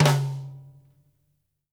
SNARE+HIGH-L.wav